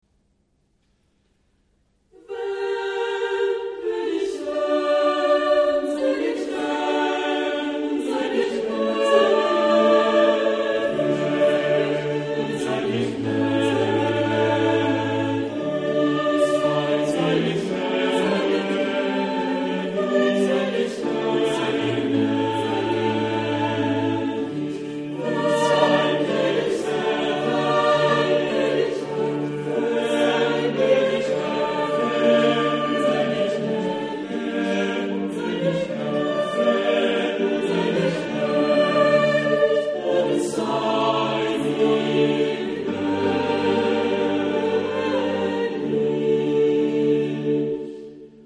Epoque: 17th century  (1600-1649)
Genre-Style-Form: Sacred ; Baroque ; Motet
Type of Choir: SSATB  (5 mixed voices )
Instrumentation: Continuo  (1 instrumental part(s))
Instruments: Harpsichord (1) or Organ (1)
sung by Dresdner Kammerchor conducted by Hans-Christoph Rademann